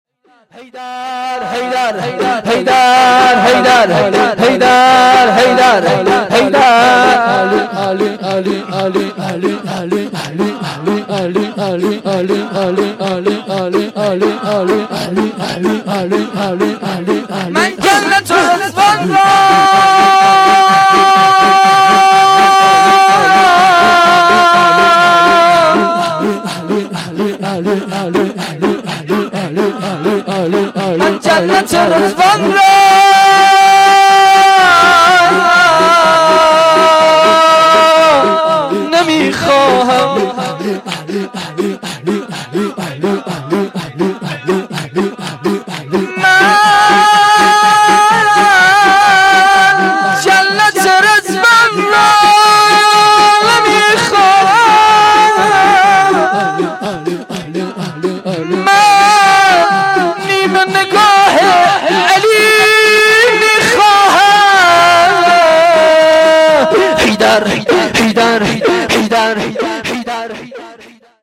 گزارش صوتی جلسه رحلت امام
روضه